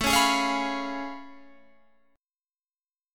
A+M9 chord